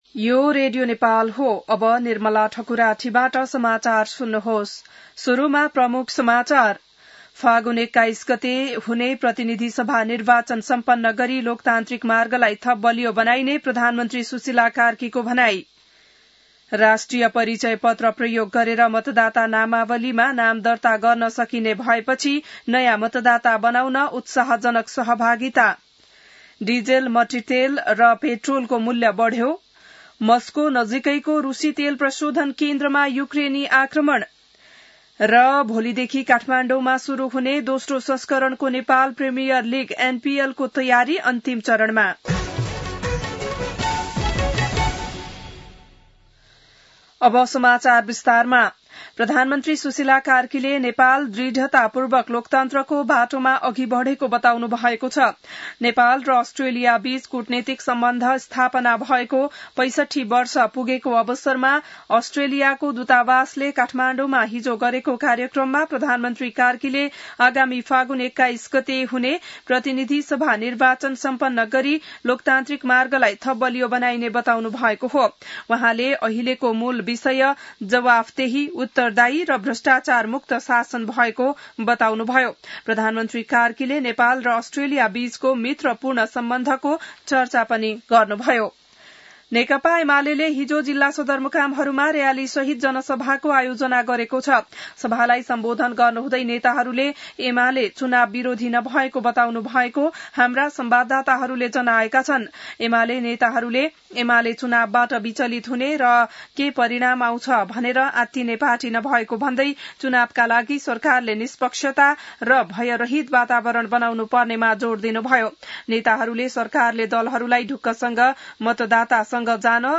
बिहान ९ बजेको नेपाली समाचार : ३० कार्तिक , २०८२